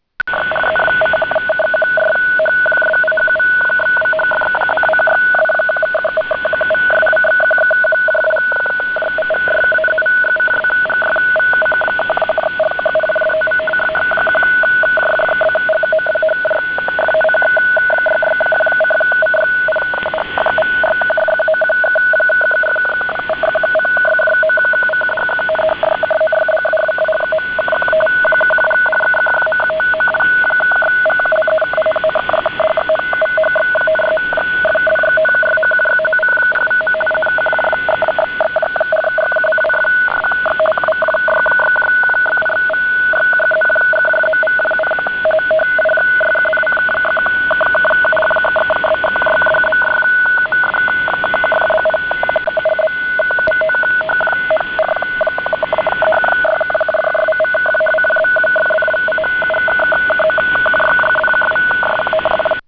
FSK-2, разнос 850 Гц, скорость 50 Бод
Начало » Записи » Радиоcигналы классифицированные
FSK2 dF=850 Hz Baurate 50 bps